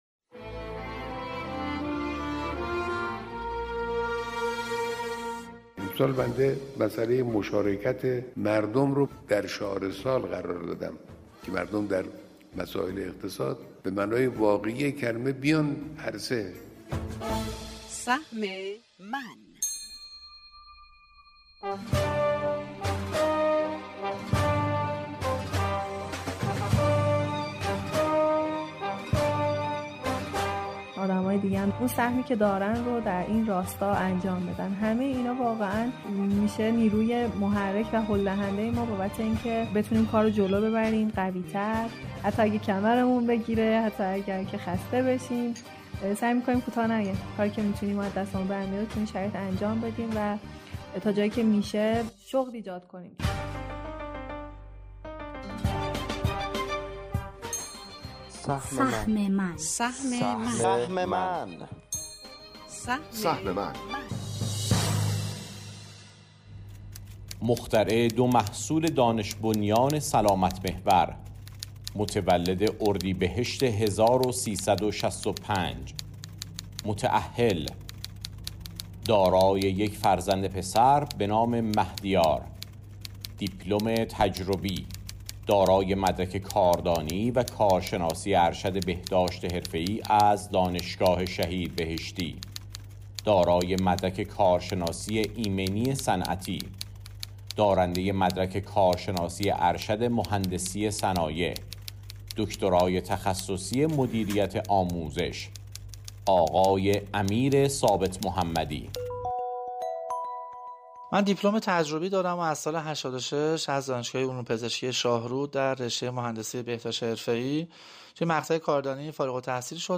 بررسی ثبت اختراع خودکار ارگونومی و نقش آن در بهبود سلامت، کاهش هزینه‌های صنعت و حمایت از تولید ملی در برنامه تخصصی رادیویی با تحلیل کارشناسی